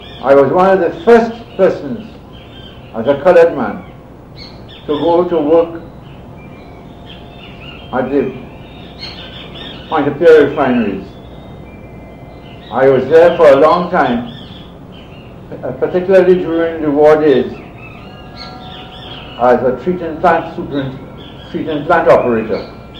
5 audio cassettes
The Oral and Pictorial Records Programme (OPReP)